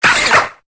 Cri de Coupenotte dans Pokémon Épée et Bouclier.